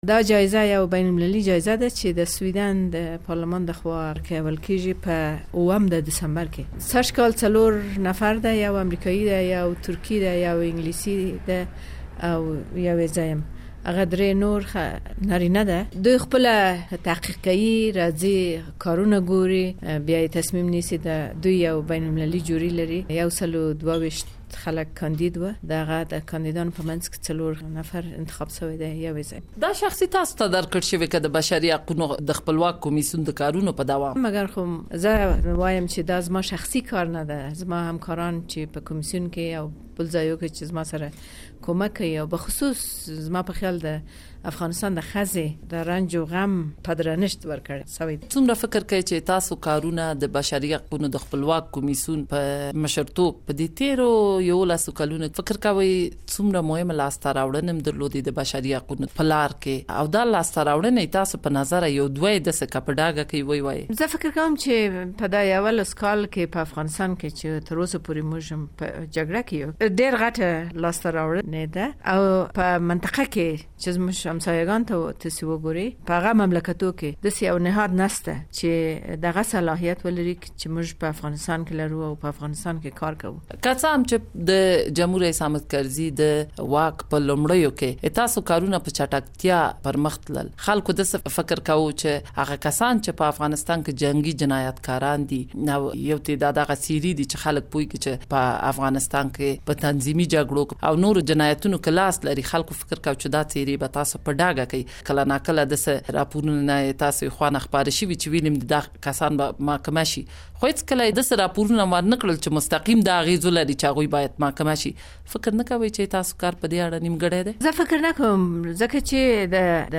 د وروستیو درېیو لسیزو د پېښو په اړه له سیما سمر سره مرکه